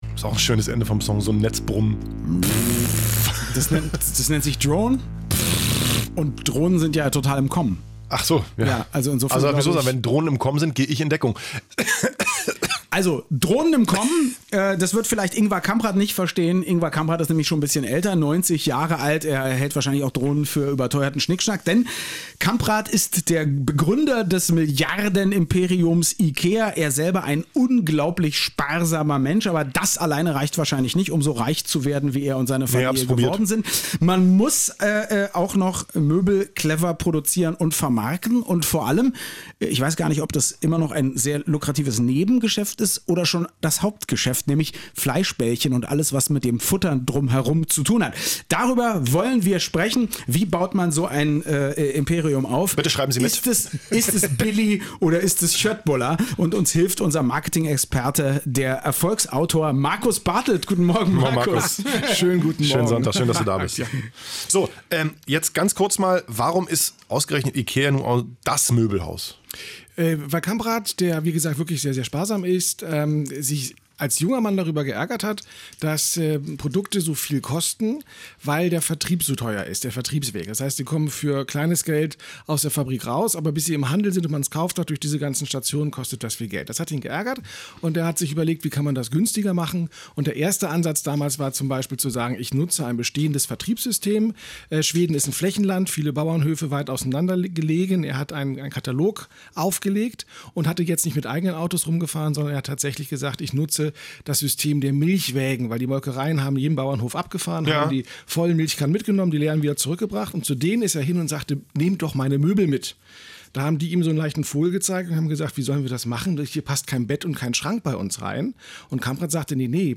Und damit nicht genug, haben sie mich auch noch ins radioeins– Studio geladen, um über IKEA und Köttbullar zu sprechen: